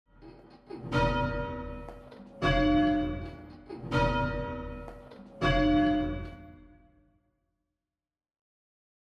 Minor 3rd Intervals
A good example of a minor third is the opening of Greensleeves.